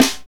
Index of /90_sSampleCDs/Northstar - Drumscapes Roland/KIT_Hip-Hop Kits/KIT_Rap Kit 3 x
SNR H H S06L.wav